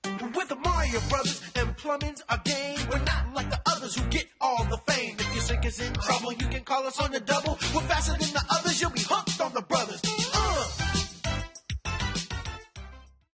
6. 1. 주제가